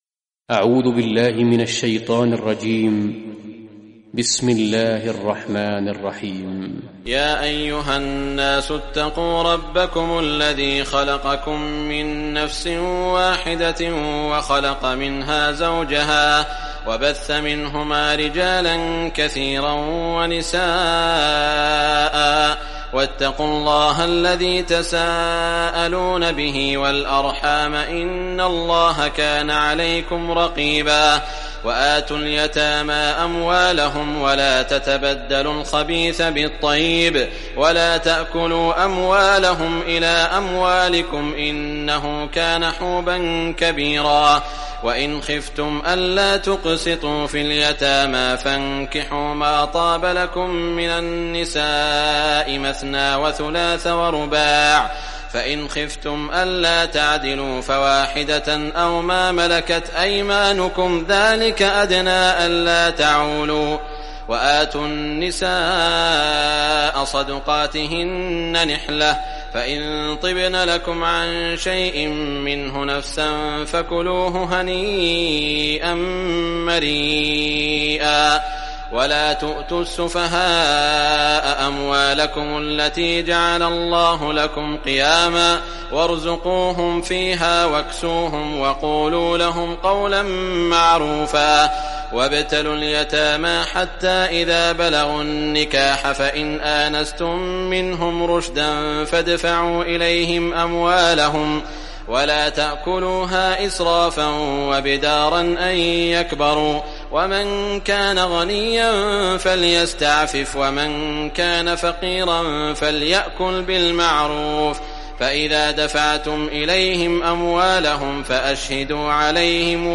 Surah An-Nisa Recitation by Sheikh Shuraim
Surah An-Nisa, listen or play online mp3 tilawat/recitation in the beautiful voice of Sheikh Shuraim.